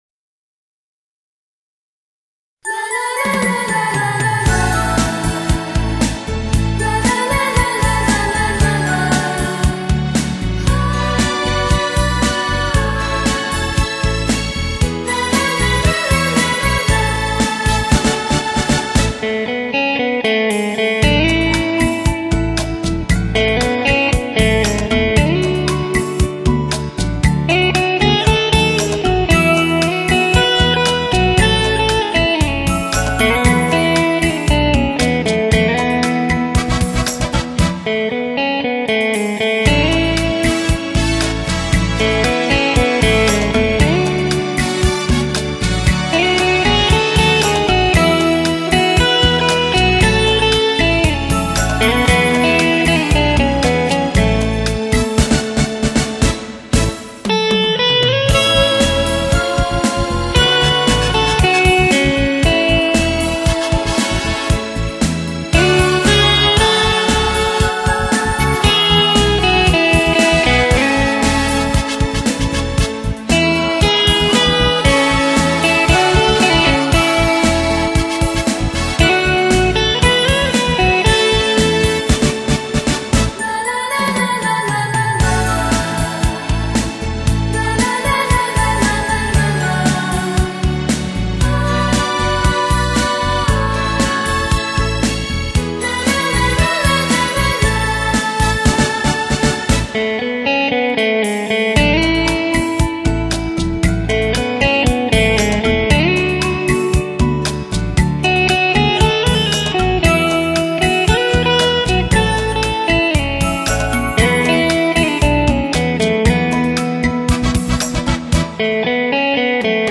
吉他